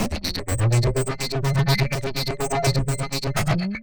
Processed_32(125BPM).wav